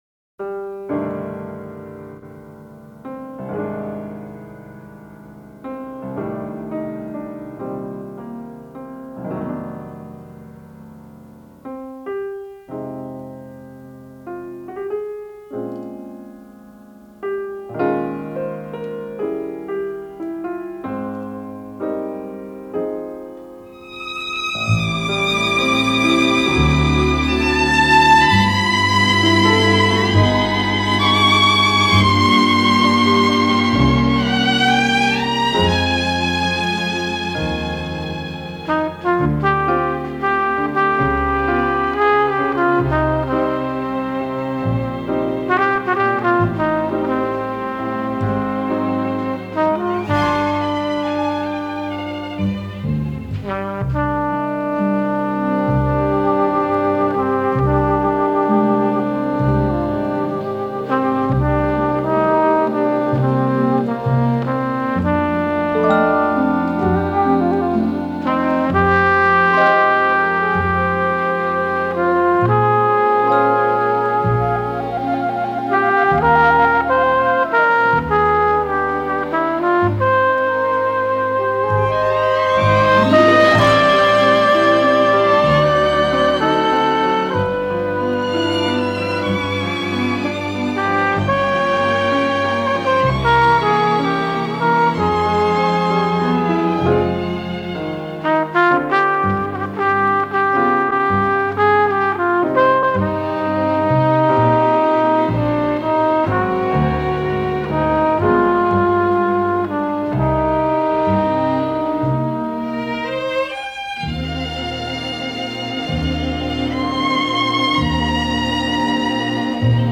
Жанр: Джаз